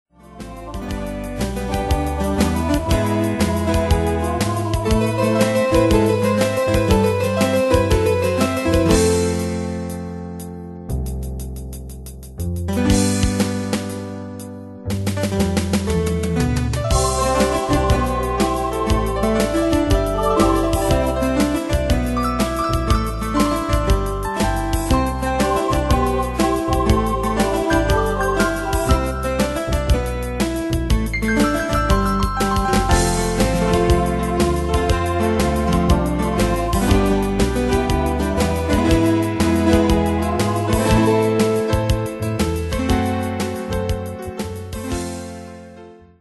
Danse/Dance: Québecois Cat Id.
Pro Backing Tracks